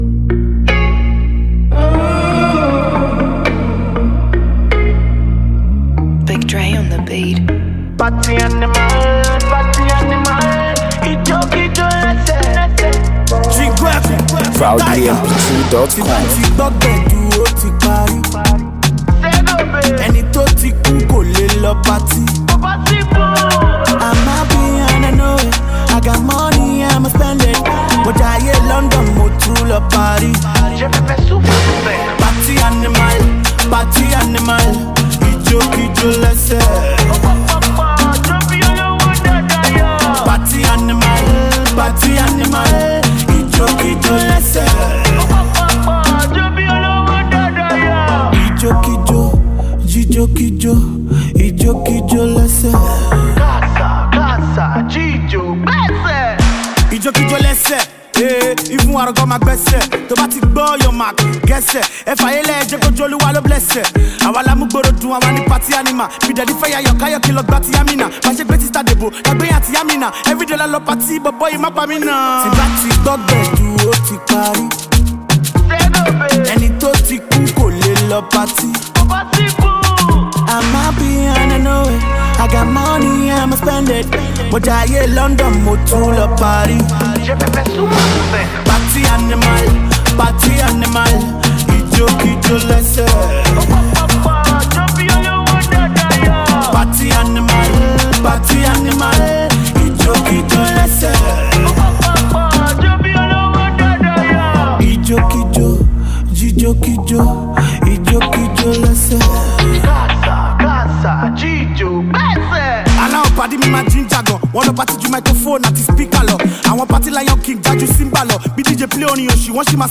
groovy dance single
Rap and Afro-Pop